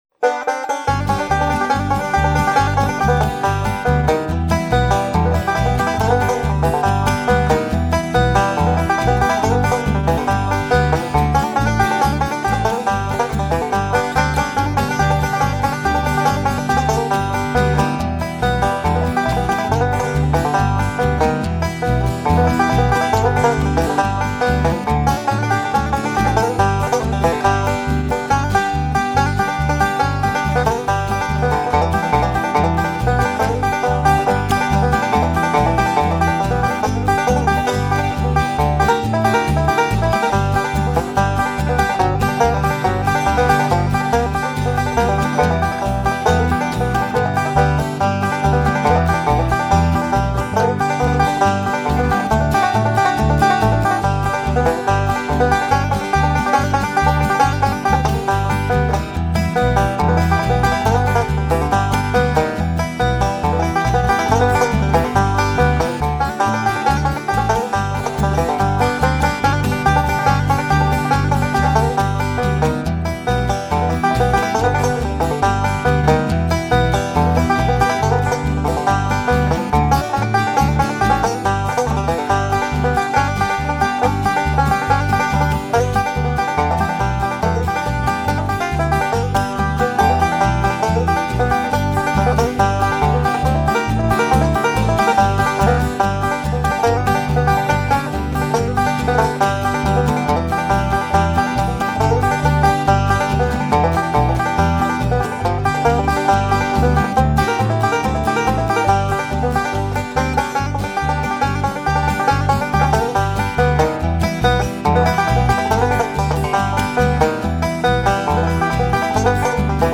Banjo Lessons:
- "Scruggs-style" fingerpicking rolls